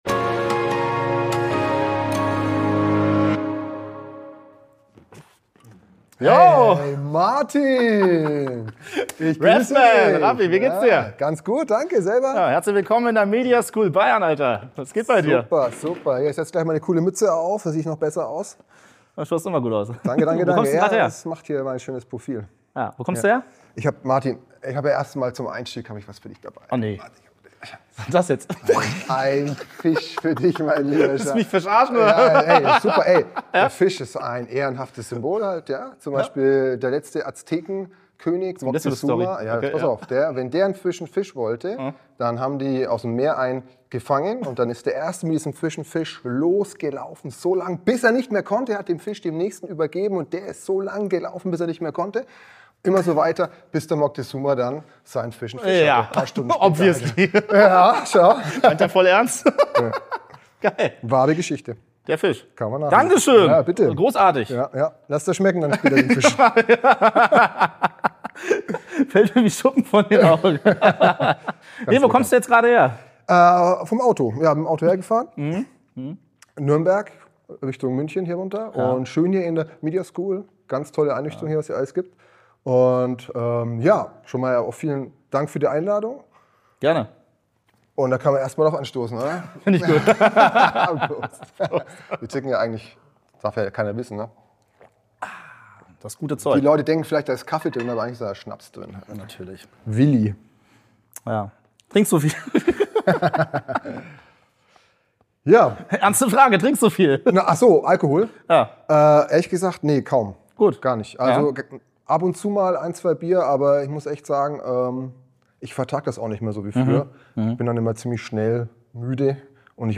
Beschreibung vor 1 Jahr Zusammenfassung Das Gespräch findet in der Mediaschool Bayern statt